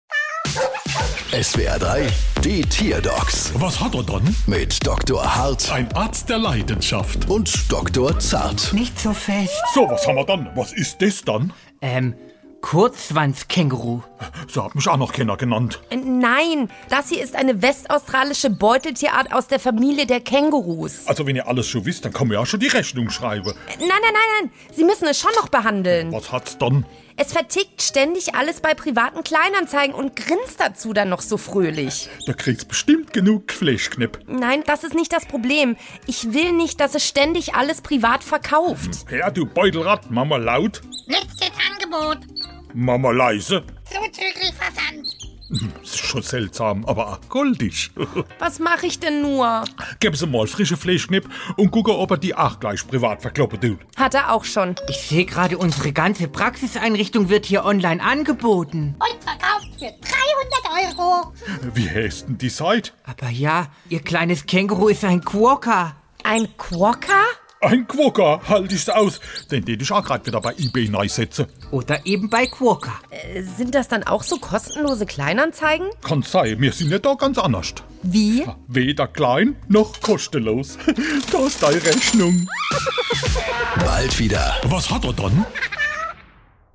SWR3 Comedy Die Tierdocs: Quokka verkauft alles